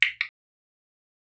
claquement-5.wav